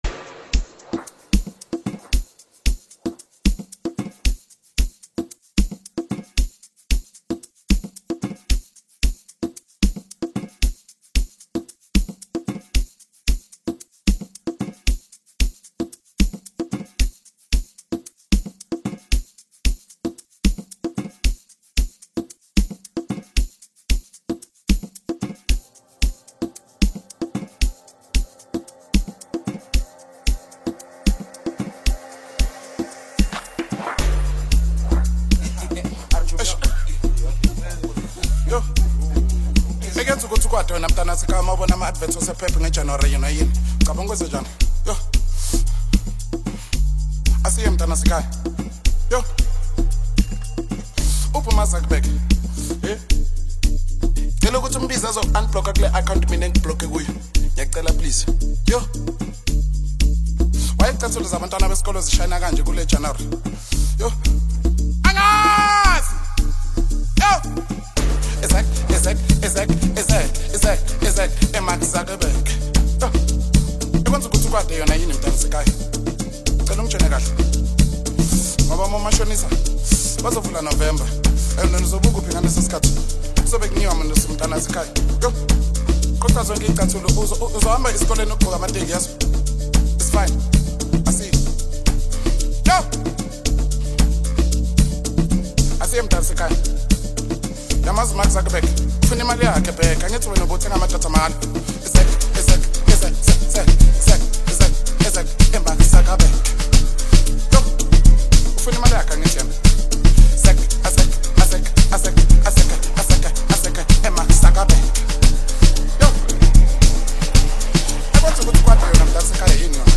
energetic Amapiano single